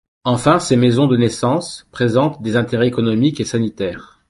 Pronunciado como (IPA)
/ɛ̃.te.ʁɛ/